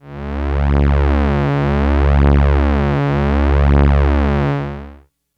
Analog Drone 02.wav